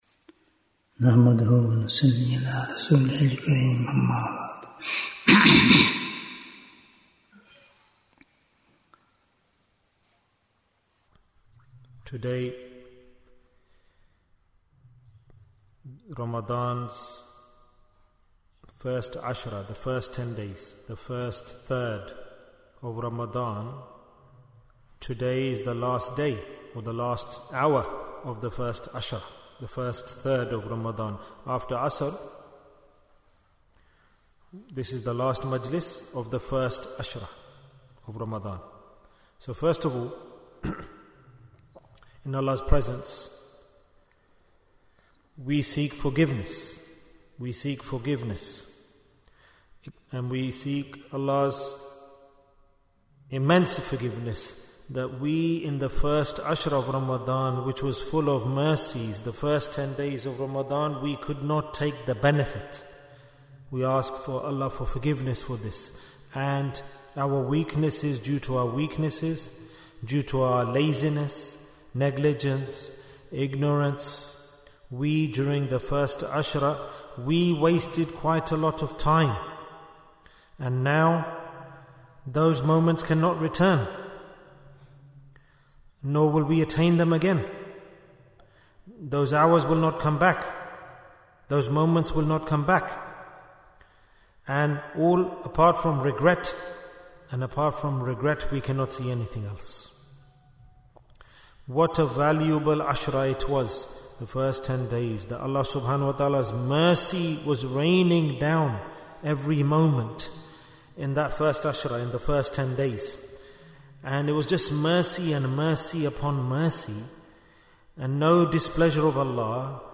Jewels of Ramadhan 2025 - Episode 13 - What are the Signs of Allah's Pleasure? Bayan, 50 minutes10th March, 2025